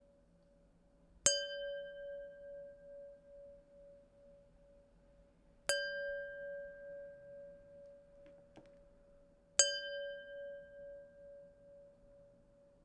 Tapping wine glas
bell ding glas OWI ring ringing ting tone sound effect free sound royalty free Sound Effects